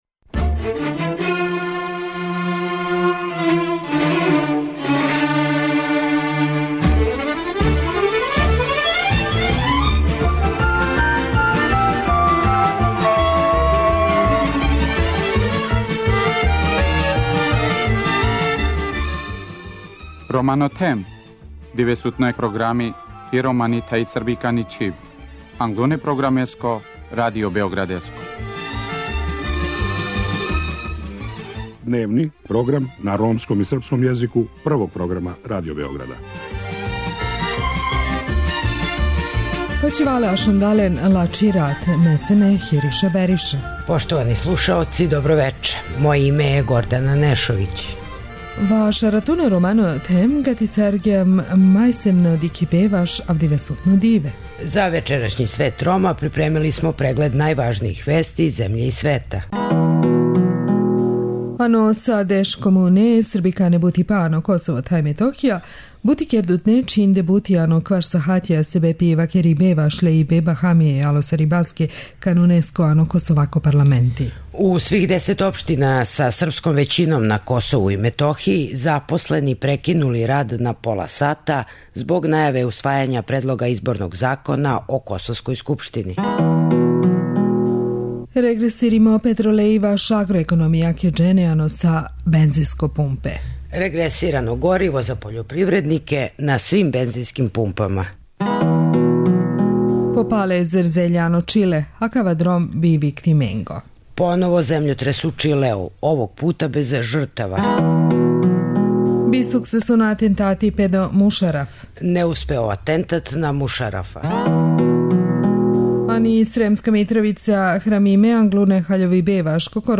Настављамо серију разговора са младима који истражују положај ромских ученика током образовања.